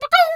pgs/Assets/Audio/Animal_Impersonations/chicken_2_bwak_10.wav at master
chicken_2_bwak_10.wav